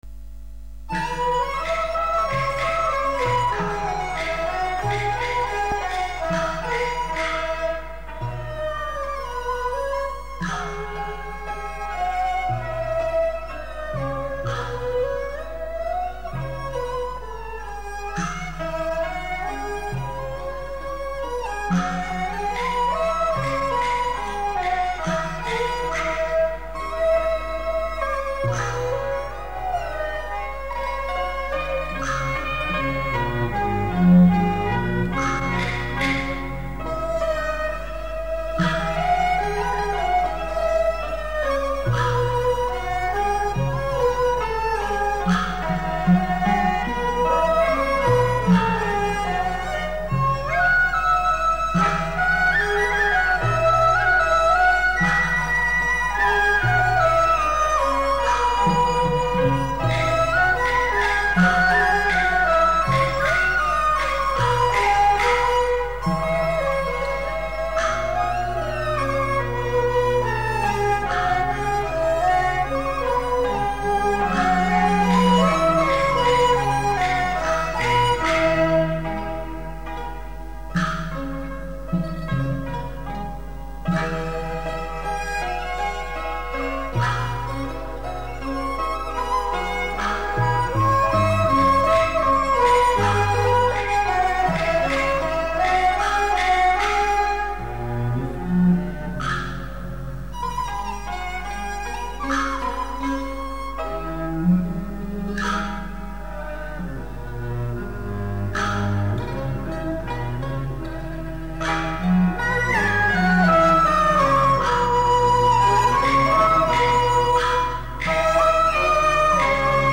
最初是卡式磁带
现在送上这段唱腔的伴奏音乐，希望有用   点播放键可播放
伴奏音乐